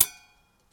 ding hit metal ring ting tone sound effect free sound royalty free Sound Effects